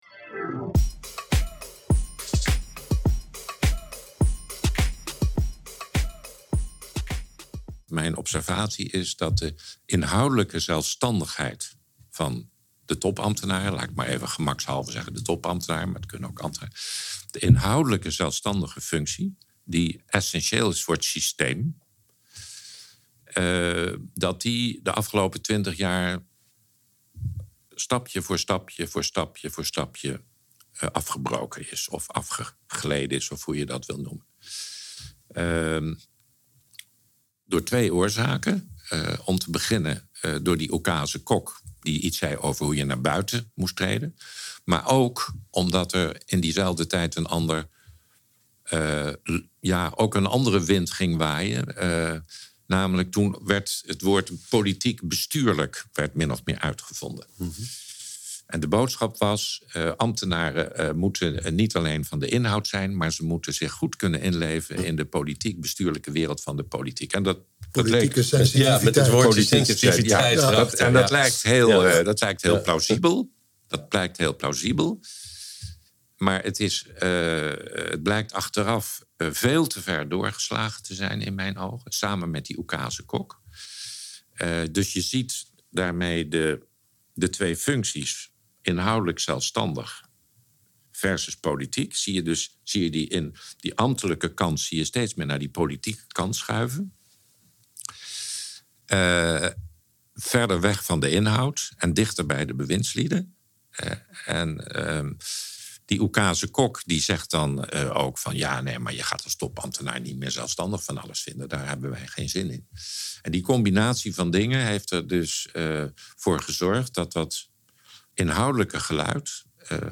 Audiobeschrijving